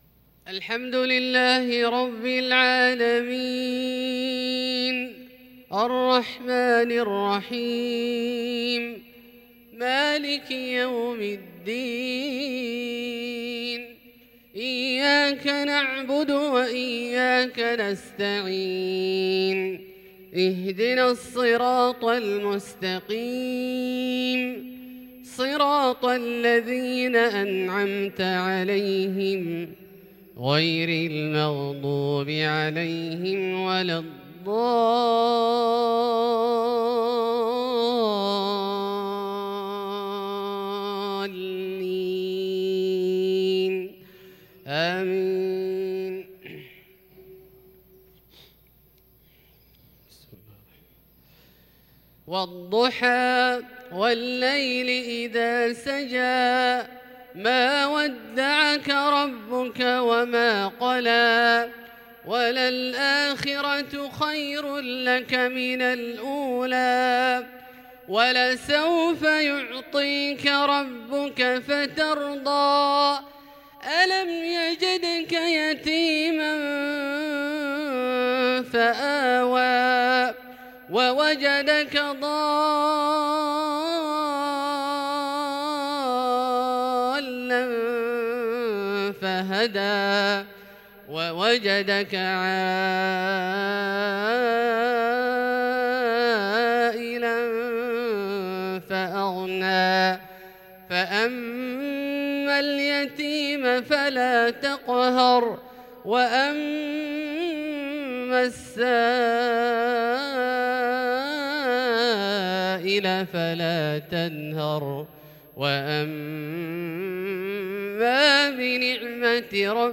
صلاة المغرب ٢-٣-١٤٤٢ | سورتي الضحى والشرح > ١٤٤٢ هـ > الفروض - تلاوات عبدالله الجهني